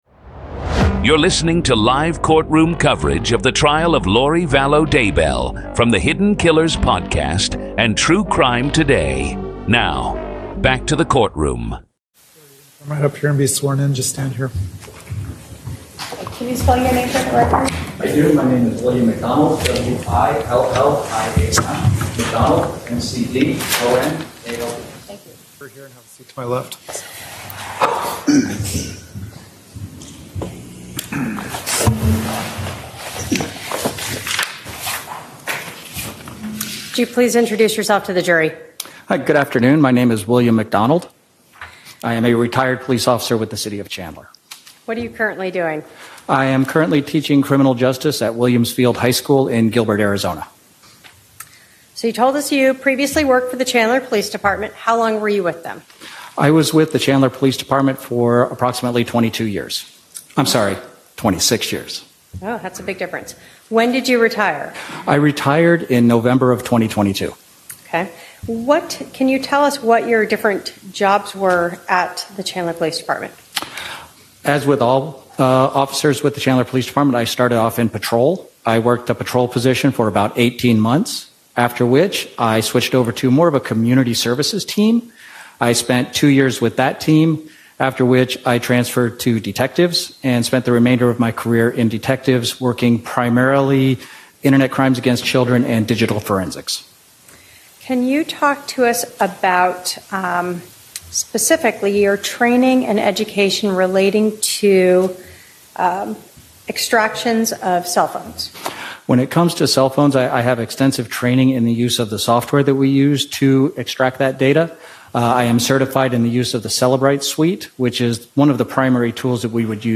Today on Hidden Killers, we bring you the full, raw, unfiltered courtroom coverage from Day 4 of Lori Vallow Daybell’s murder trial in Arizona—exactly as it happened.
This is the complete courtroom audio from April 10, 2025 —no edits, no filters, just the raw reality of one of the most bizarre and disturbing murder trials in modern history.